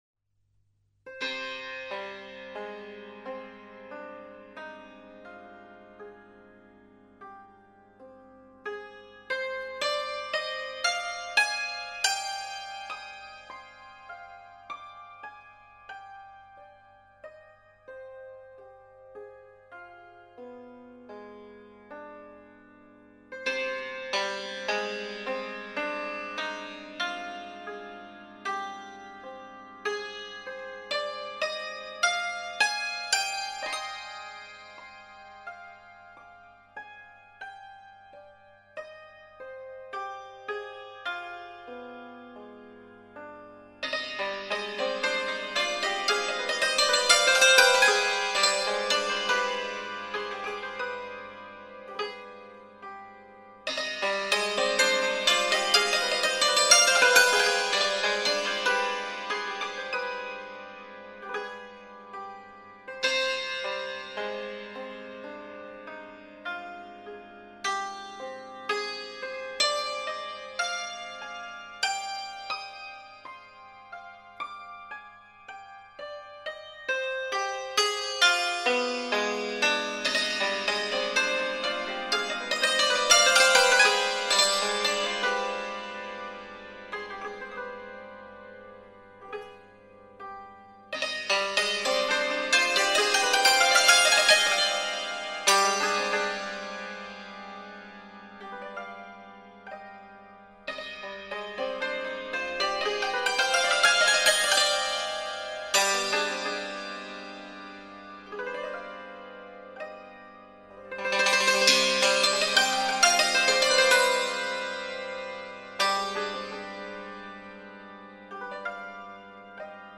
World , Instrumental فرمت